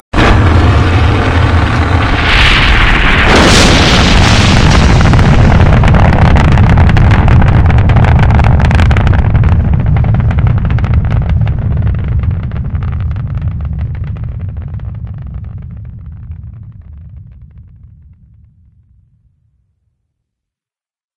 rocketTakeoff.ogg